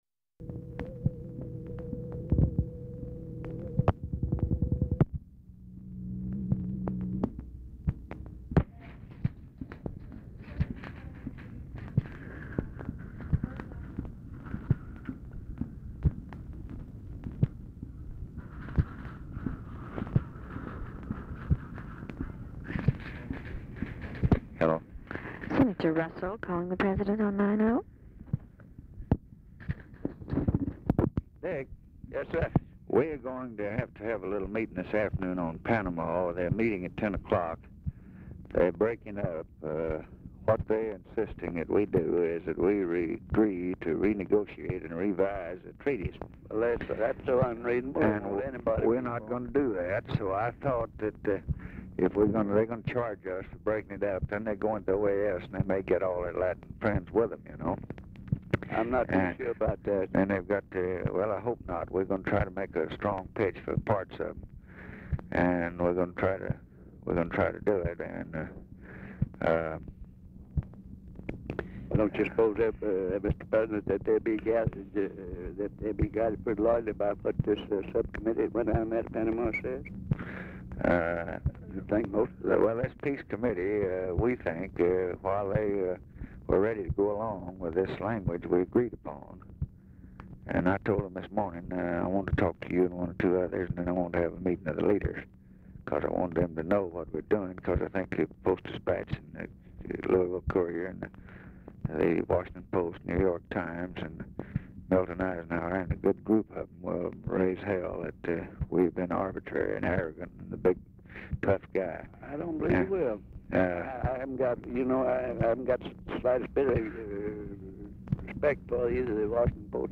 Telephone conversation # 1612, sound recording, LBJ and RICHARD RUSSELL, 1/29/1964, 10:30AM | Discover LBJ
Format Dictation belt
Oval Office or unknown location